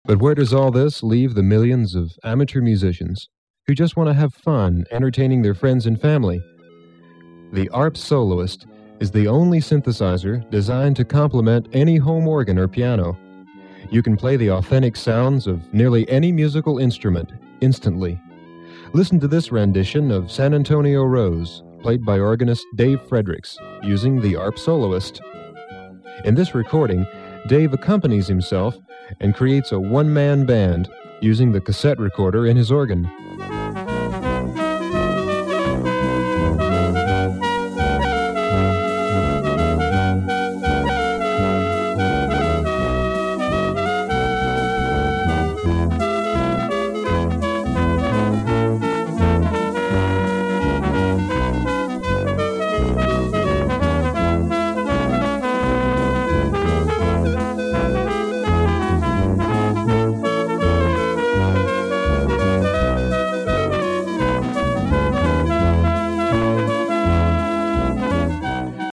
ARP 2600 Demo Tape - Home